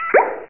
1 channel
pop.mp3